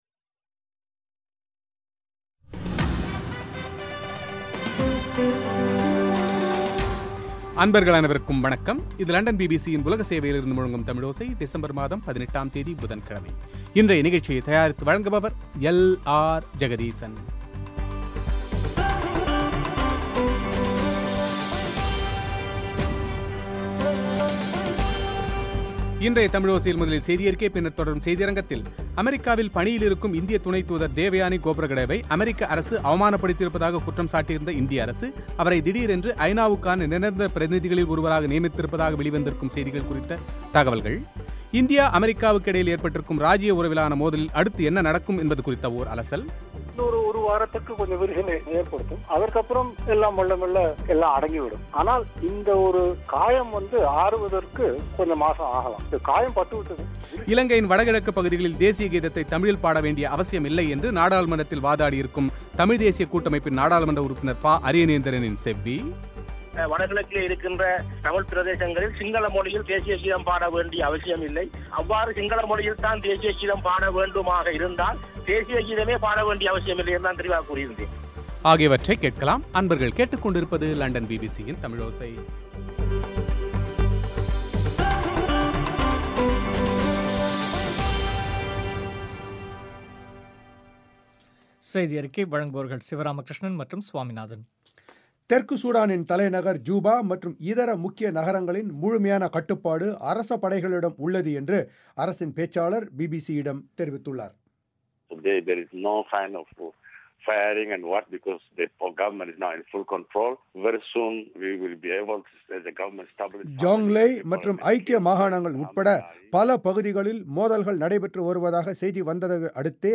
இலங்கையின் வடக்கு கிழக்குப் பகுதிகளில் தேசிய கீதத்தை தமிழில் பாட வேண்டிய அவசியம் இல்லை என்று நாடாளுமன்றத்தில் வாதாடியிருக்கும் தமிழ்த் தேசியக் கூட்டமைப்பின் நாடாளுமன்ற உறுப்பினர் பா. அரியநேத்திரனின் செவ்வி;